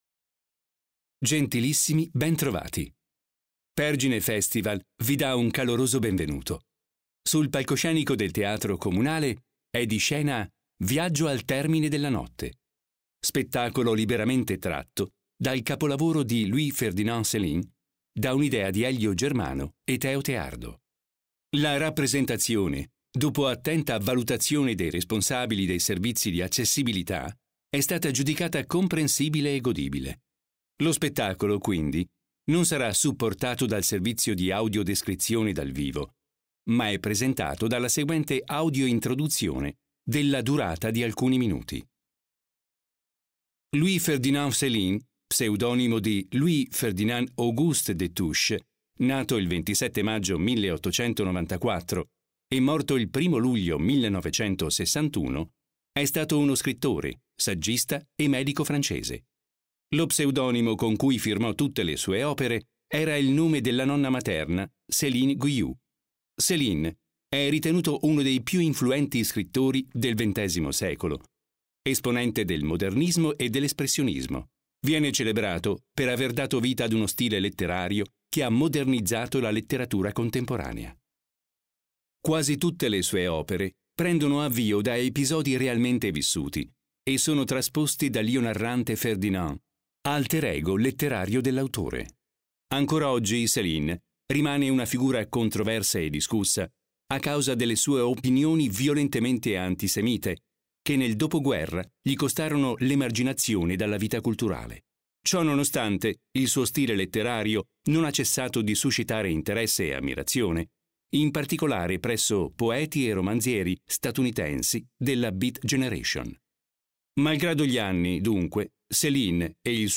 Audio-introduzione per non vedenti